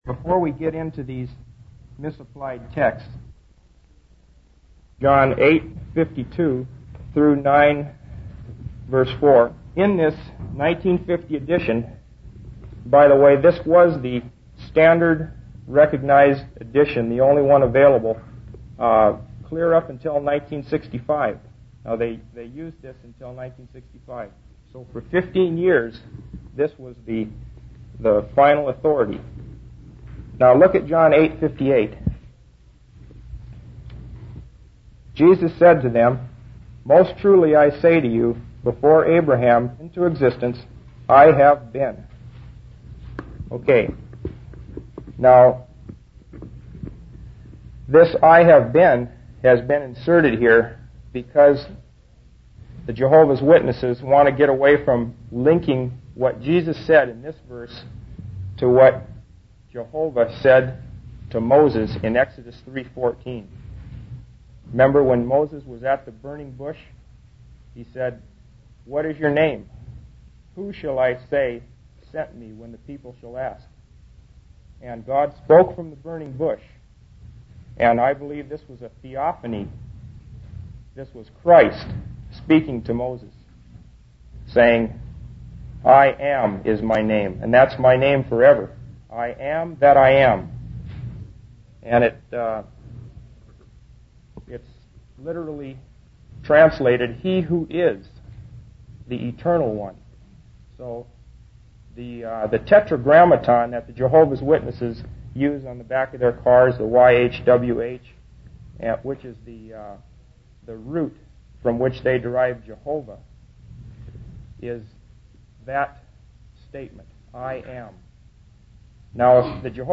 In this sermon, the preacher emphasizes that worshiping riches is not the same as worshiping God. He refers to Isaiah 43-45 and Galatians 4:8 to highlight the importance of recognizing that there is only one God by nature. The preacher also discusses the authority of the Watchtower and the need to break it down in order to interpret verses correctly.